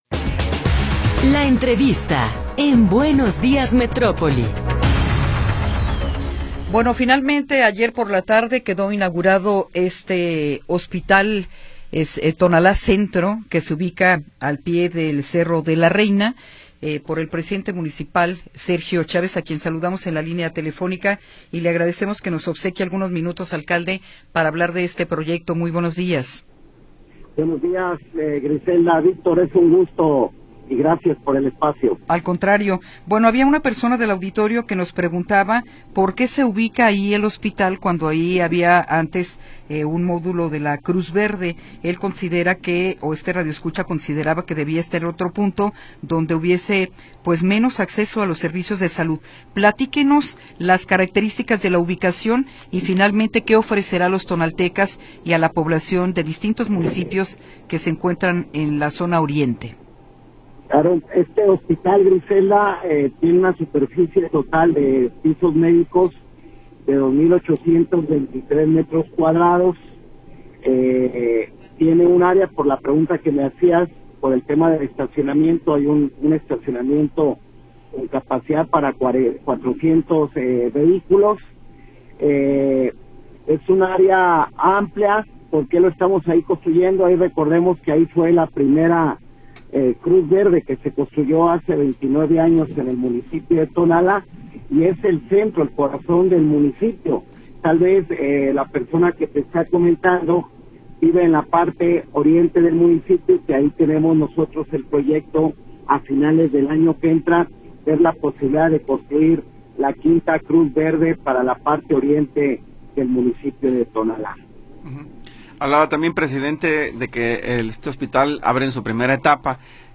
Entrevista con Sergio Chávez Dávalos